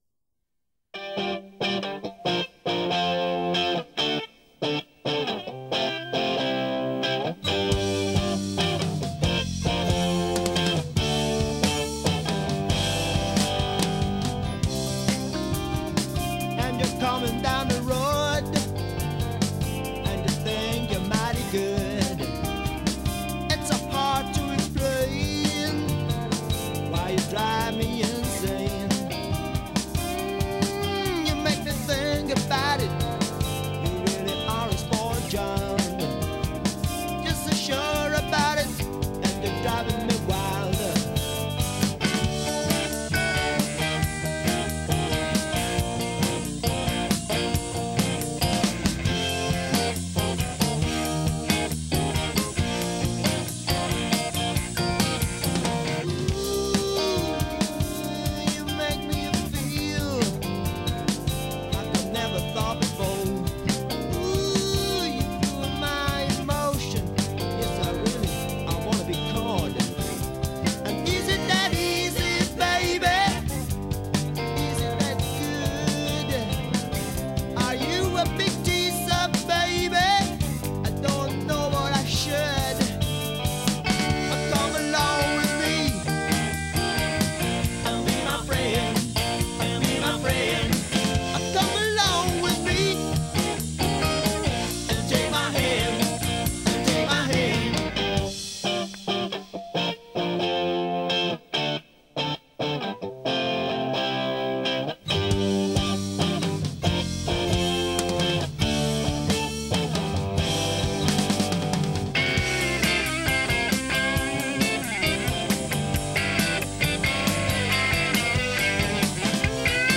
Rock'n'roll - das tut wohl
Schlagzeug